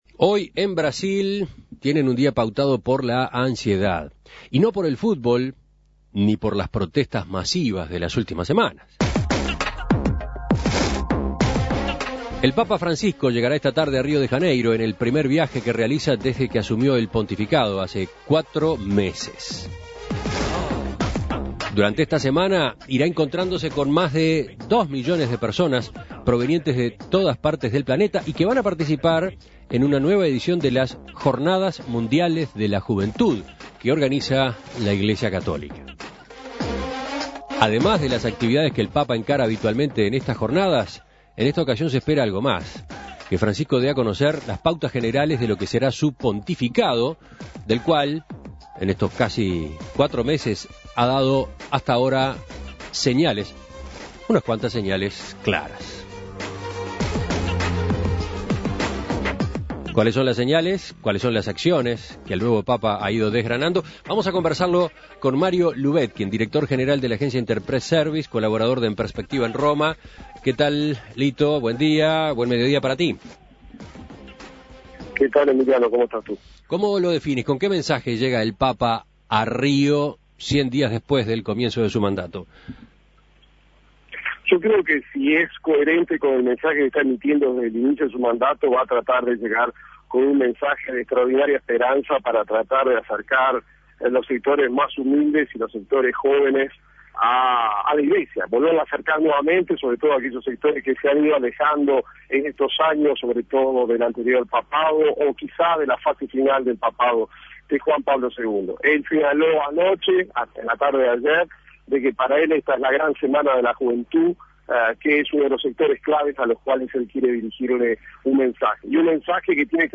Contacto con Mario Lubetkin, colaborador de En Perspectiva en Roma.